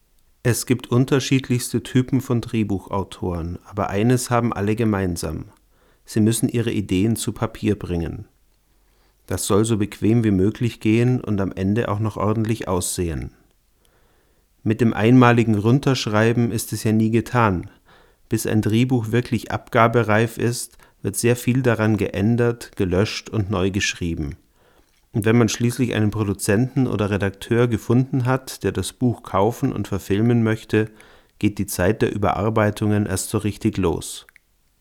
Sprachaufnahmen - Bitte um Beurteilung
Alle wurden hier in meinem Wohnzimmer (Dachwohnung mit Teppichboden) aus ca. 20 cm Abstand gemacht und anschließend in Audacity normalisiert; weitere Bearbeitung steckt noch nicht drin.